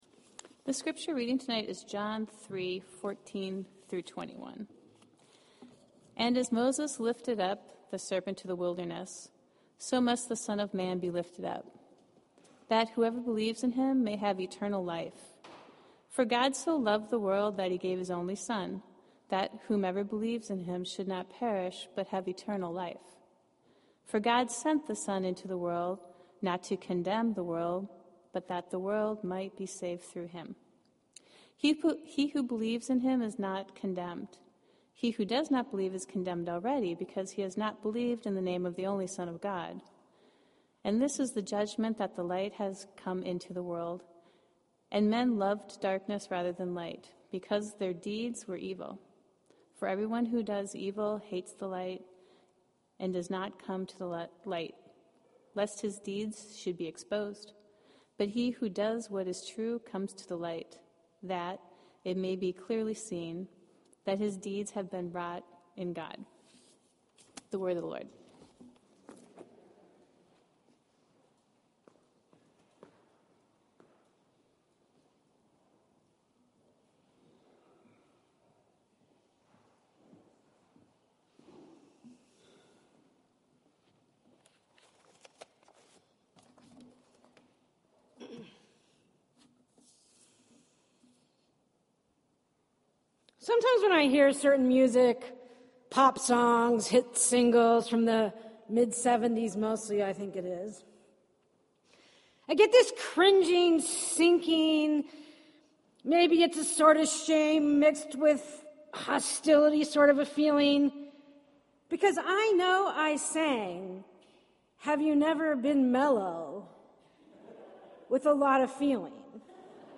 John 3:14-21 This entry was posted in Sermons .